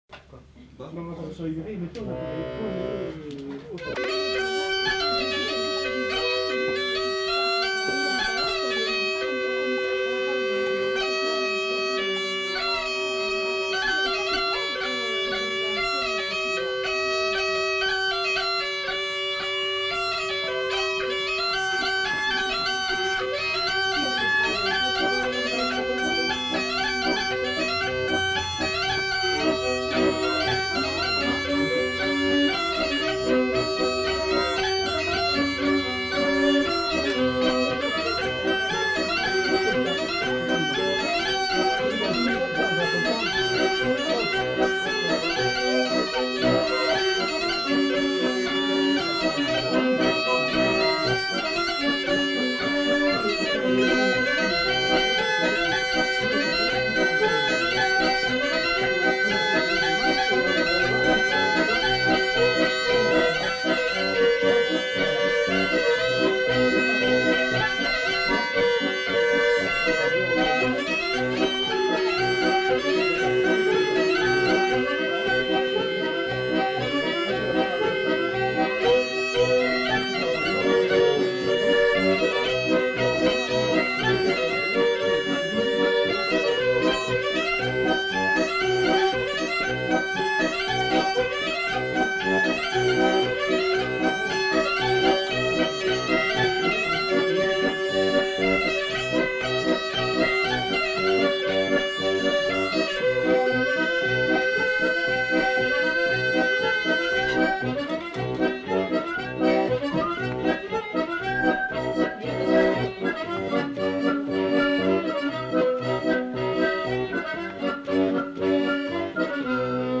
Polka piquée
Perlinpinpin fòlc (ensemble instrumental)
Aire culturelle : Agenais
Lieu : Foulayronnes
Genre : morceau instrumental
Instrument de musique : accordéon diatonique ; boha ; violon
Danse : polka piquée